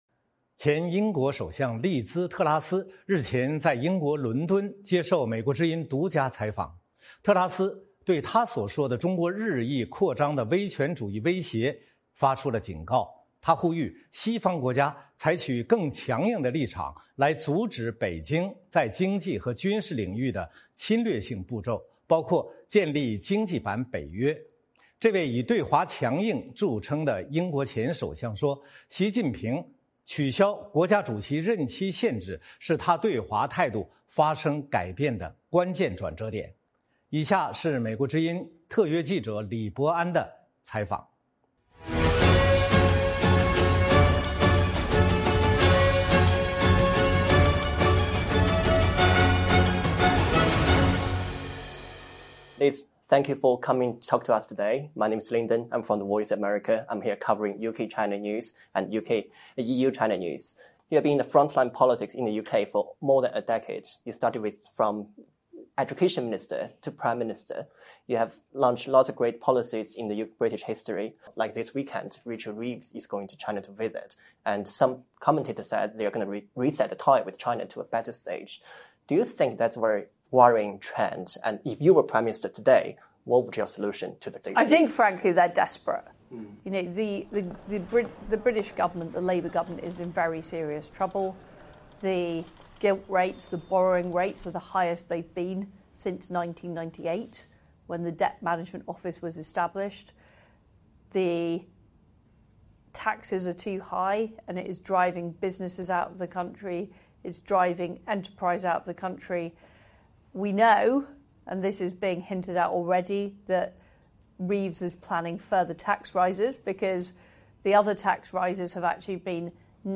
专访前英国首相特拉斯：应对中国的唯一方式是展示经济和军事实力
前英国首相利兹·特拉斯接受美国之音专访，她认为中国日益扩张的威权主义威胁值得关注，呼吁西方以更强硬的立场阻止北京在经济和军事领域的侵略性步骤，包括建立“经济版北约”。这位以对华强硬著称的英国前首相说，习近平取消国家主席任期限制是她对华态度转变的关键。